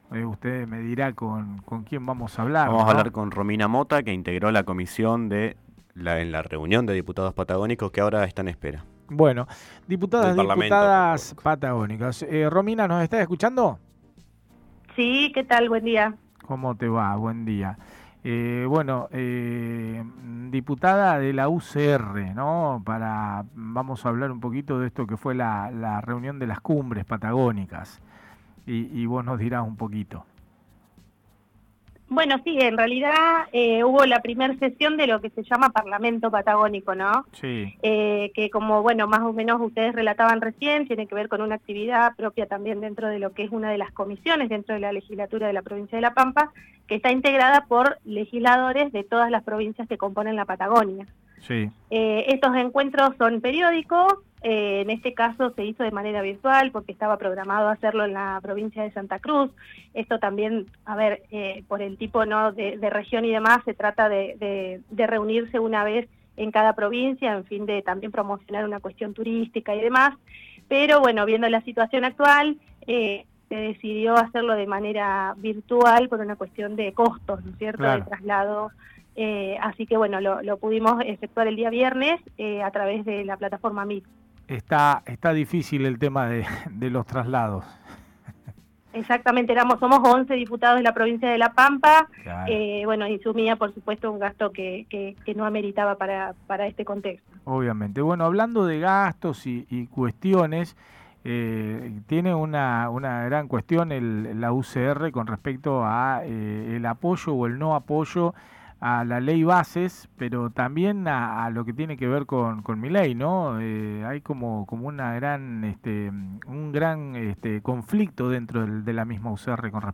NOTA CON LA DIPUTADA DE LA UCR ROMINA MOTA - LT Noticias
El día de hoy, dialogamos con la diputada de la UCR, Romina Mota, en «el mundo vive equivocado».